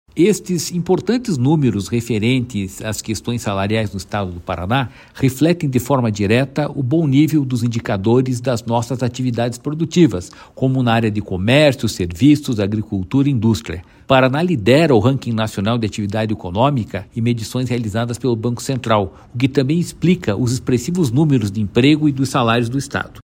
Sonora do diretor-presidente do Ipardes, Jorge Callado, sobre a evolução dos salários no Paraná